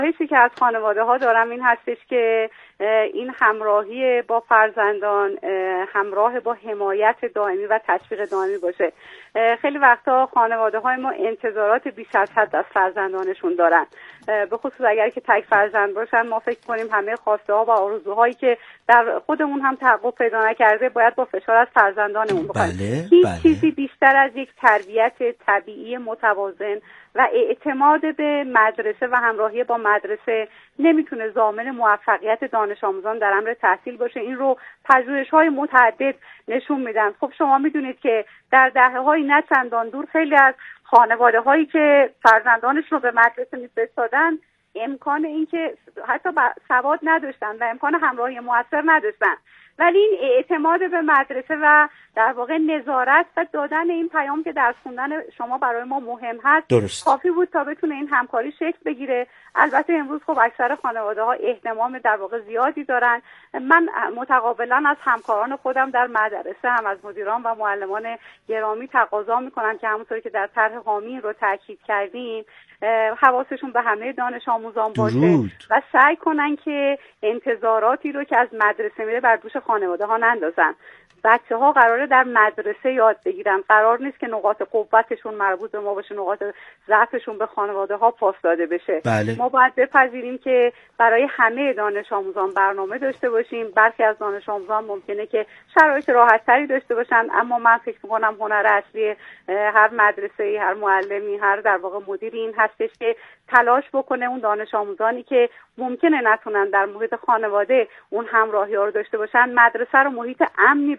ایکنا به پاس همین اهمیت والا و با درک ژرف از این مسئولیت خطیر، به گفت‌وگو با رضوان حکیم‌زاده، معاون آموزش ابتدایی وزارت آموزش و پرورش نشسته است تا از روایت‌های او در زمینه تحول آموزشی، عدالت محوری و همگامی با نظام آموزش و پروش با فناوری‌های روز در مسیر برداشتن گامی هر چند کوچک در راه اعتلای آموزش و پرورش میهن عزیزمان بگوید و بشنود.